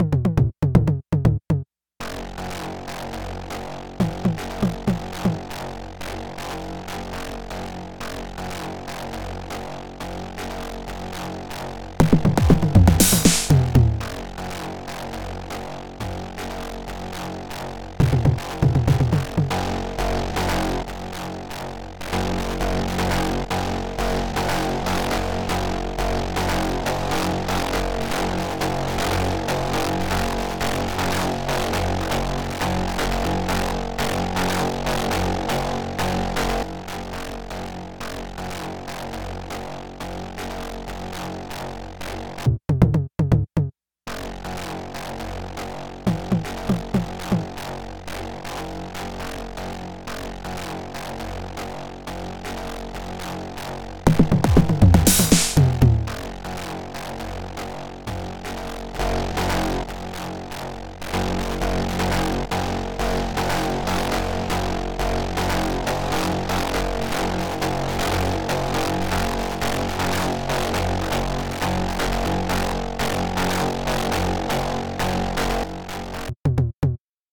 some bass with Erica Synths DB01
smooth +++++